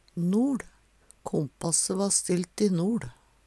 noL - Numedalsmål (en-US)